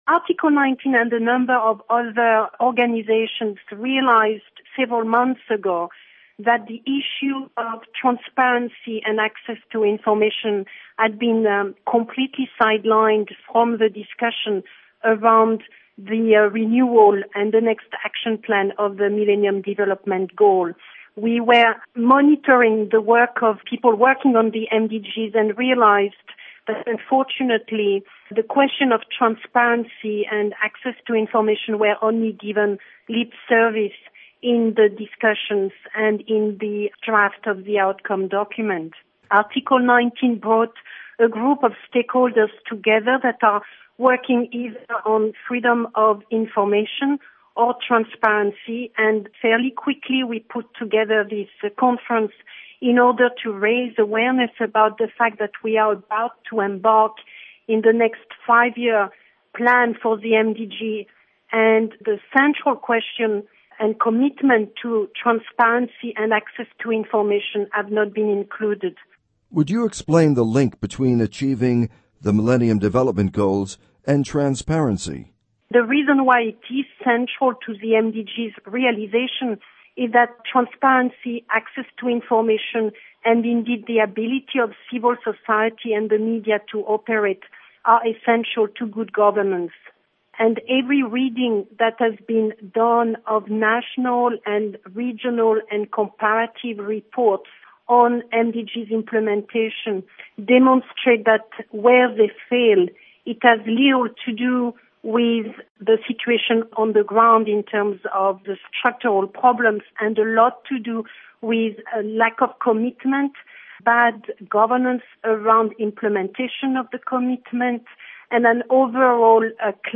interview with Agnes Callamard